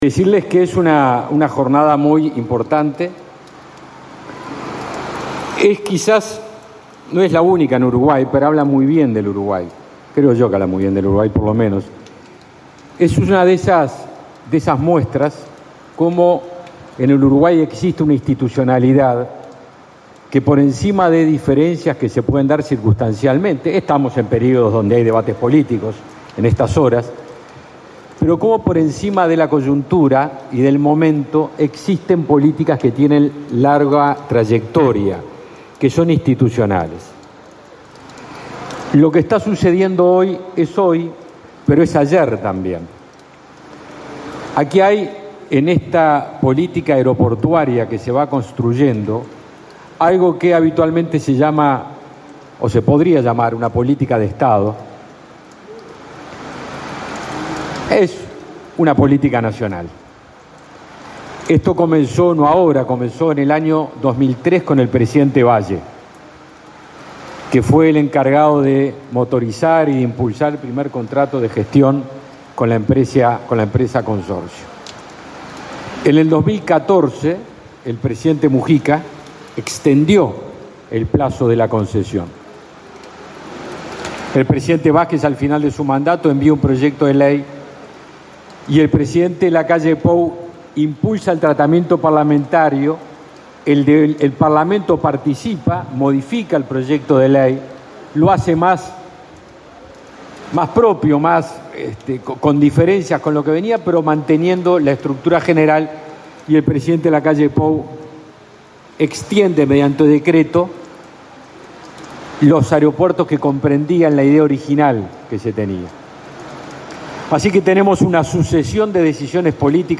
Palabras del ministro de Defensa, Javier García
El ministro de Defensa, Javier García, participó en el acto de colocación de la piedra fundamental del nuevo aeropuerto internacional de Carmelo, en